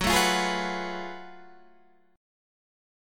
F#mM11 chord